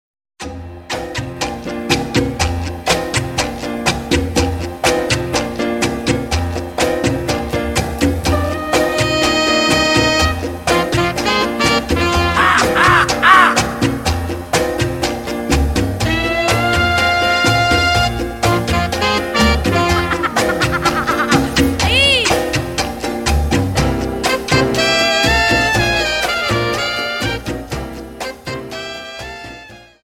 Dance: Cha Cha